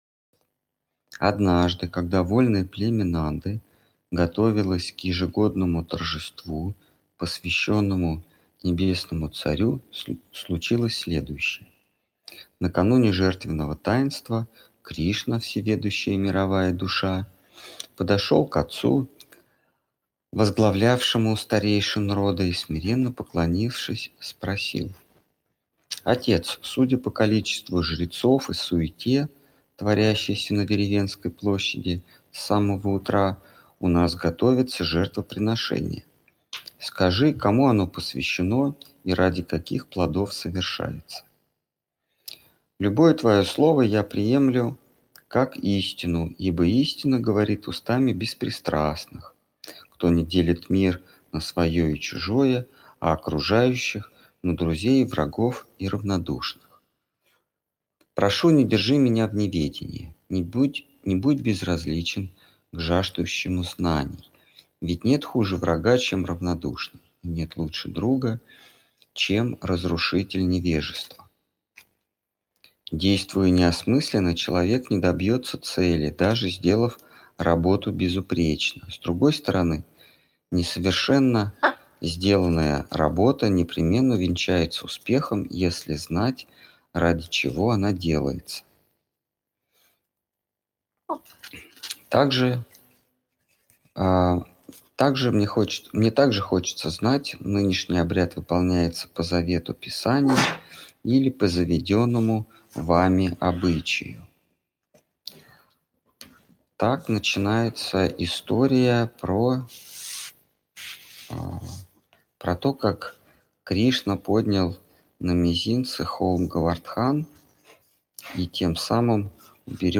Ответы на вопросы из трансляции в телеграм канале «Колесница Джаганнатха». Тема трансляции: Шримад Бхагаватам.